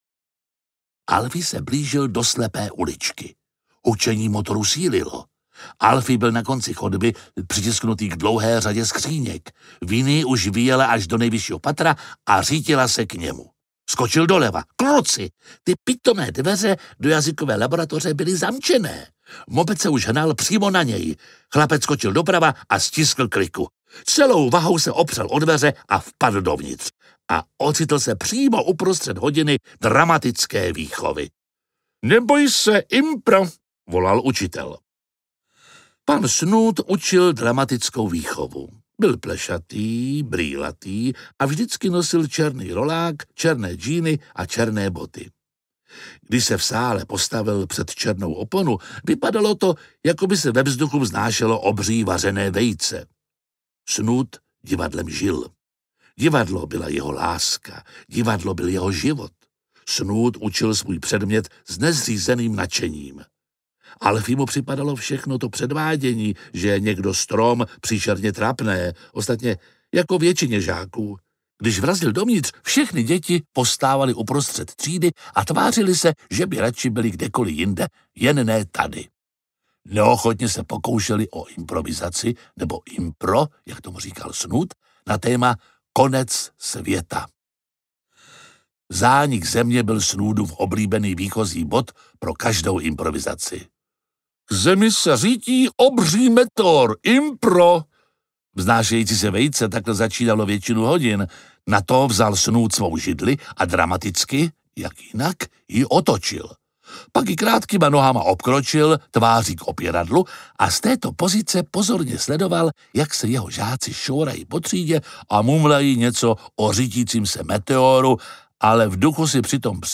Ďábelská zubařka audiokniha
Ukázka z knihy
• InterpretJiří Lábus